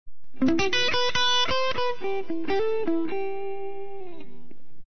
guitar_beat_3.mp3